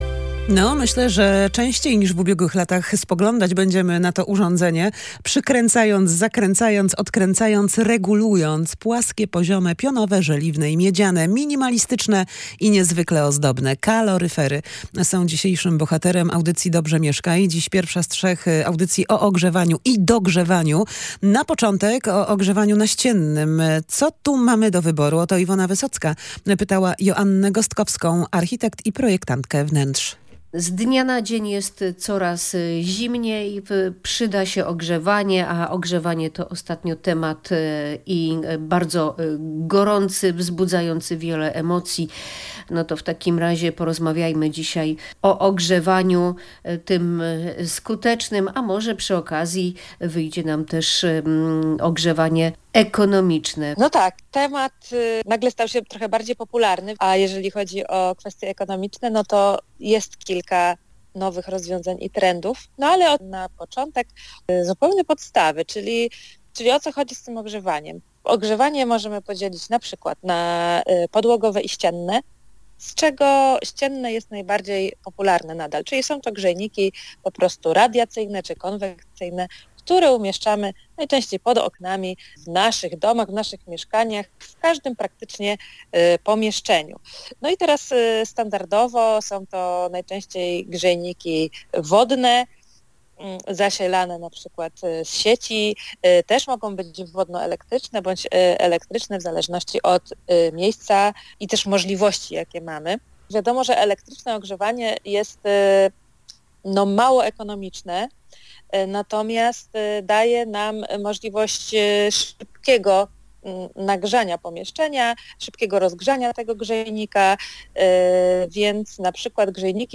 Jakie ogrzewanie naścienne wybrać? Ekspert omawia różne rodzaje kaloryferów